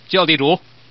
Man_Order.mp3